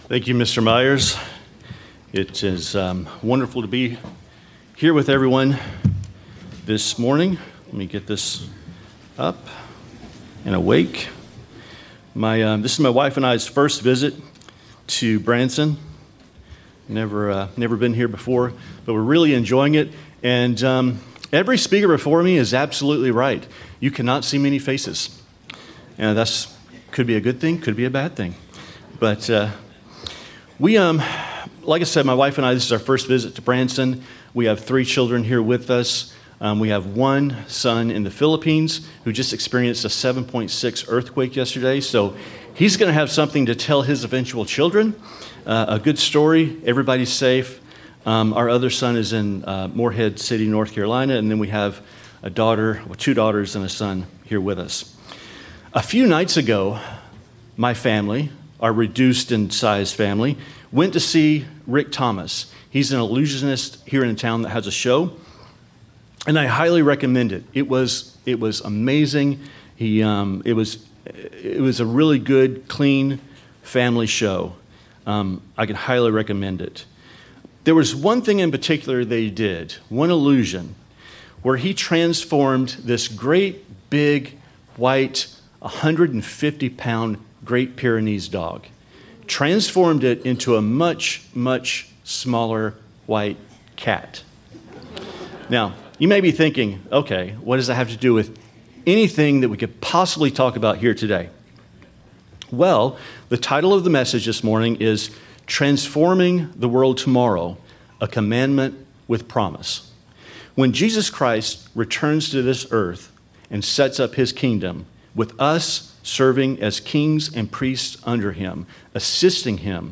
Sermons
Given in Branson, Missouri